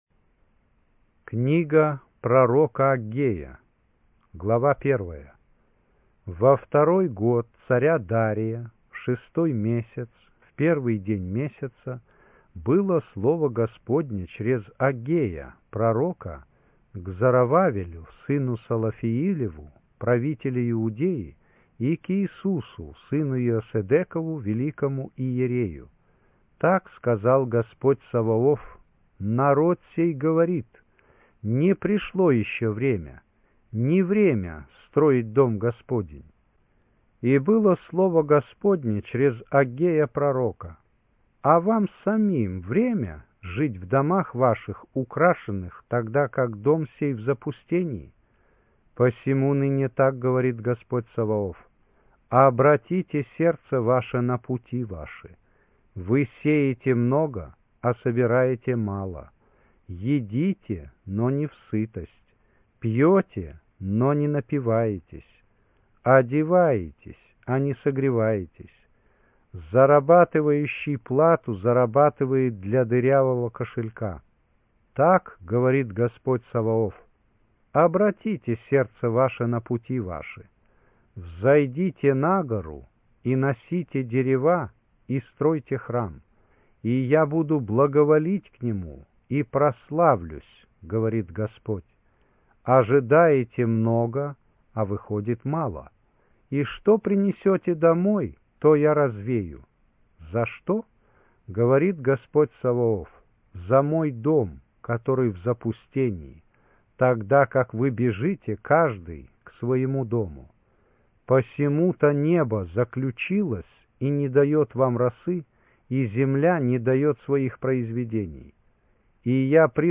Аудио Библия